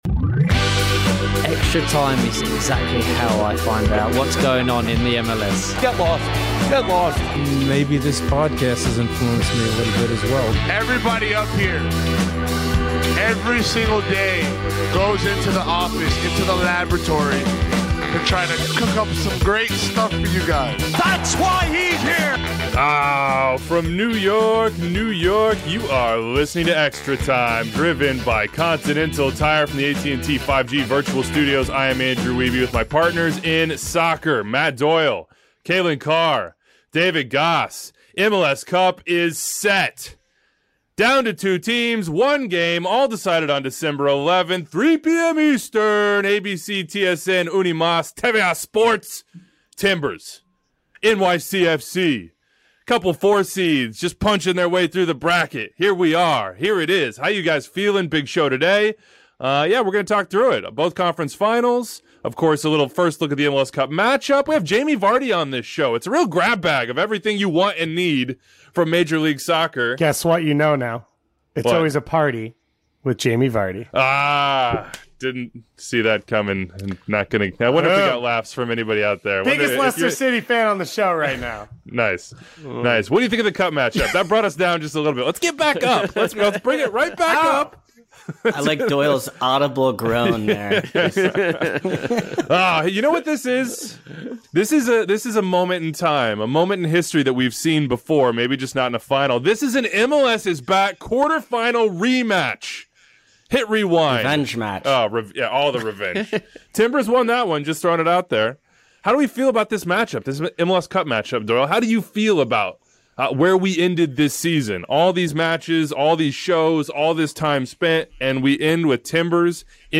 Jamie Vardy interview